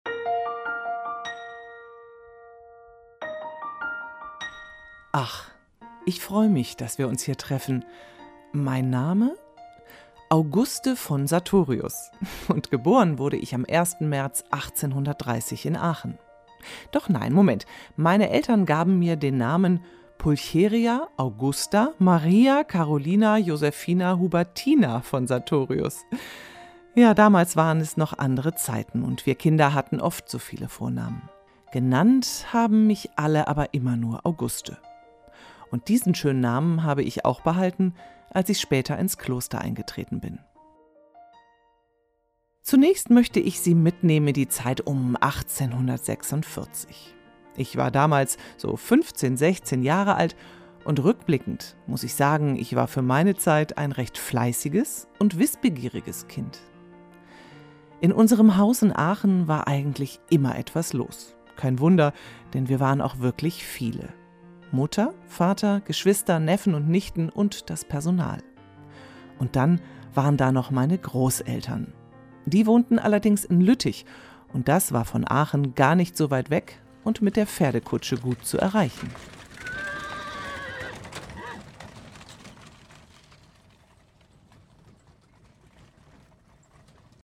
Hörspiel
1_Auguste_Hoerspiel_Erzaehlung1.27min.mp3